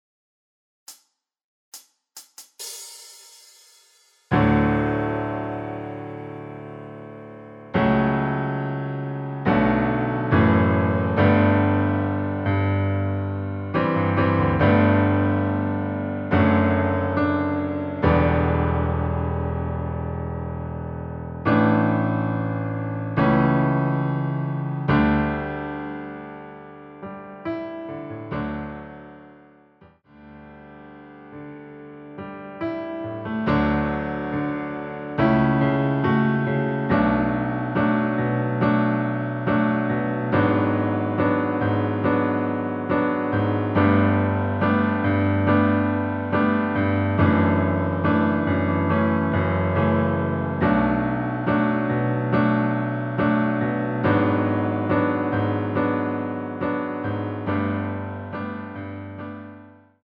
반주가 피아노 하나만으로 제작 되었습니다.(미리듣기 확인)
전주없이 노래가시작되는곡이라 카운트 만들어 놓았습니다.
원키에서 (-2)내린 (Piano Ver.) MR입니다.
앞부분30초, 뒷부분30초씩 편집해서 올려 드리고 있습니다.